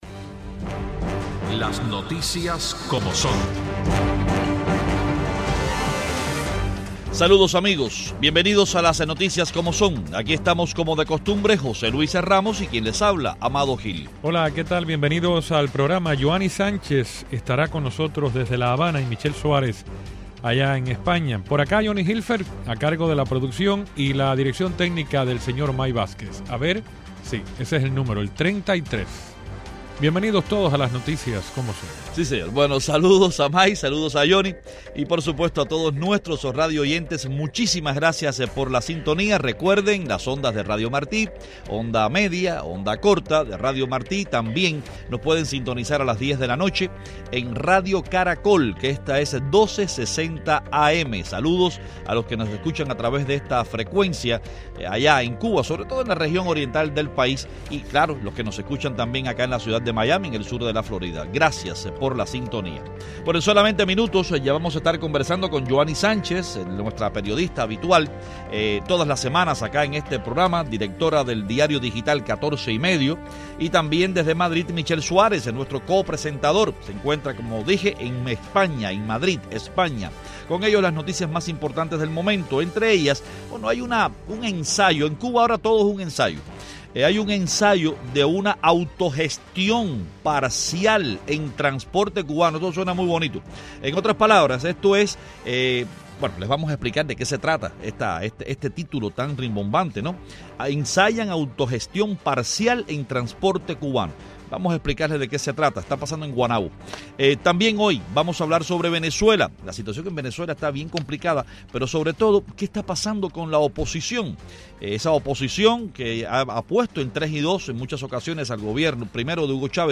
Los periodistas Yoani Sánchez, desde La Habana